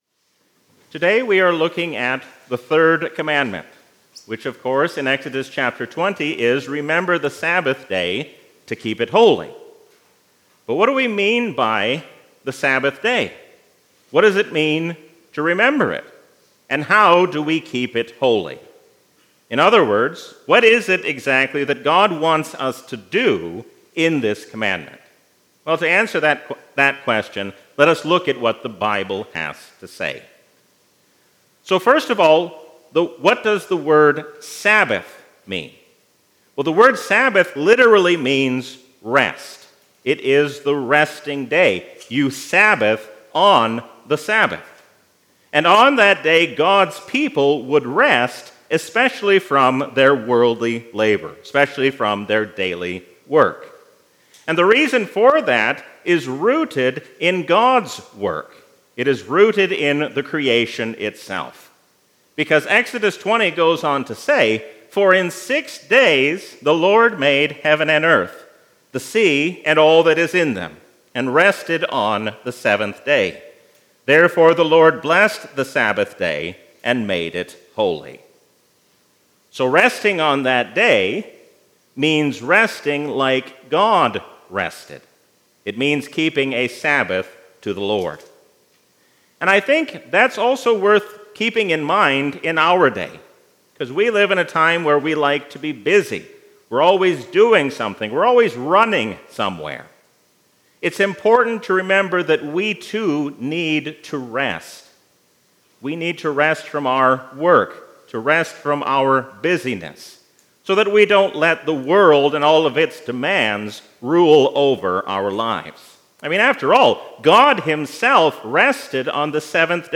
A sermon from the season "Epiphany 2023." God wants us to use His name for good and not for evil.